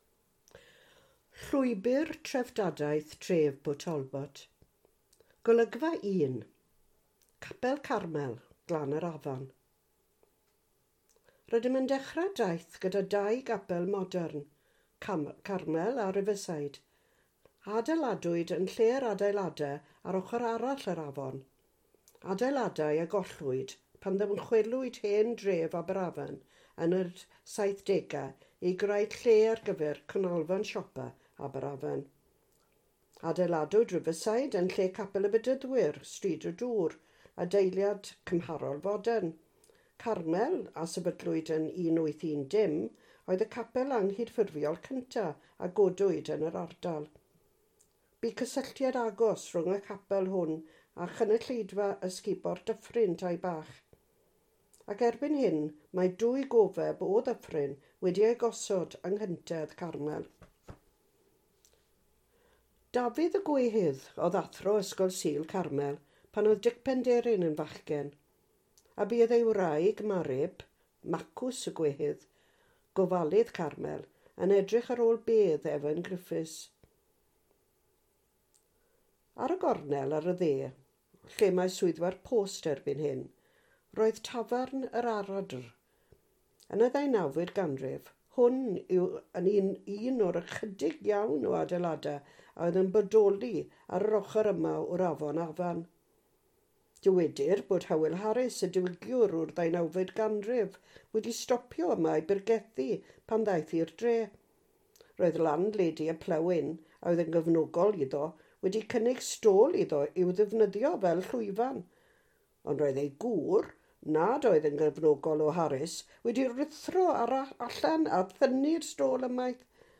Lawrlwythwch Taith Sain y Llwybr (MP3) Nesaf: Capel Carmel, Glan yr Afan